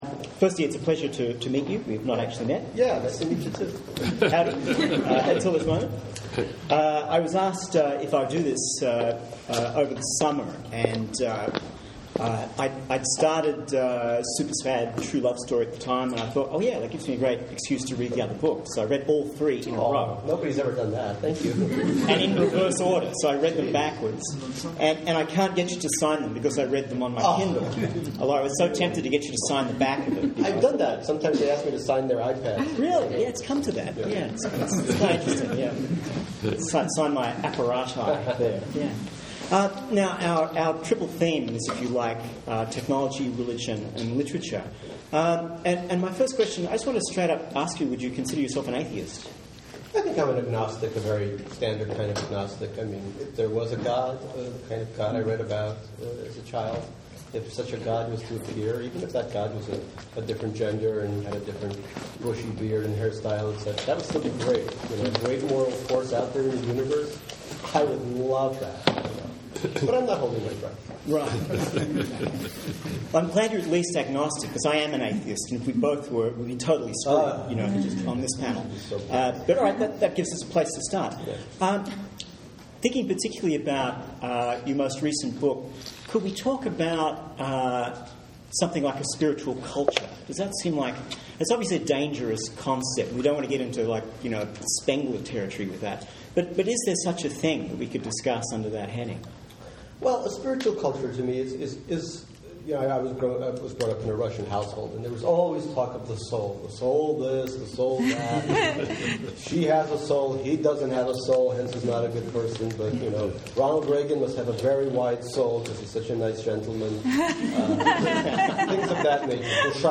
Listen to a conversation with Gary Shteyngart, author of The Russian Debutante’s Handbook, Absurdistan, and most recently Super Sad True Love Story. Moderated by McKenzie Wark, professor of media and cultural studies at The New School and author of Gamer Theory.